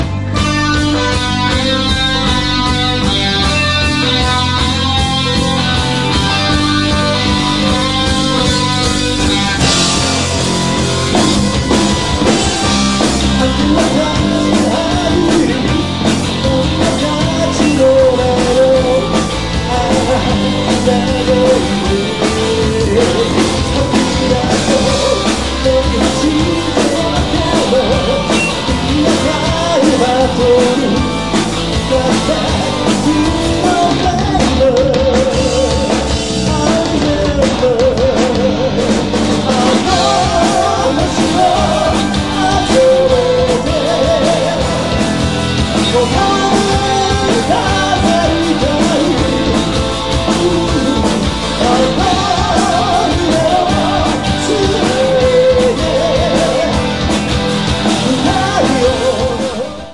vocal
guitar
keybords,chorus
bass,chorus
drums